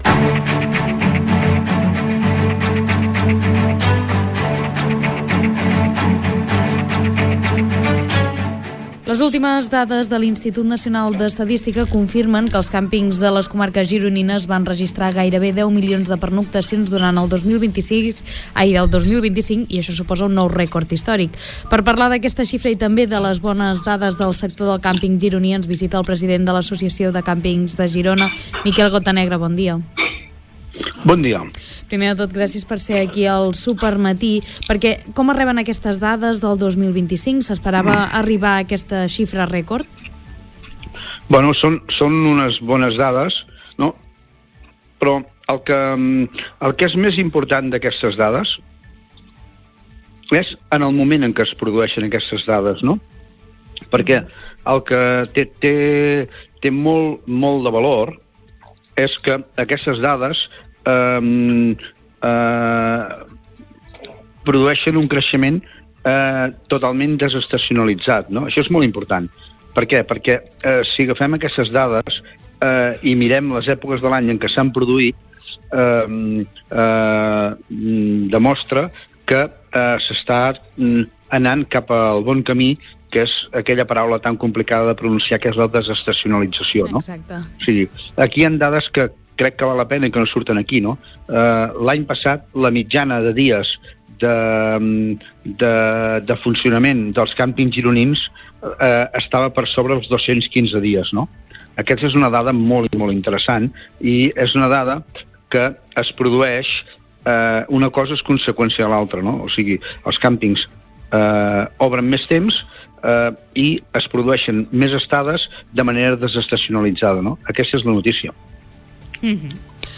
Entrevista-Miquel-Gotanegra.mp3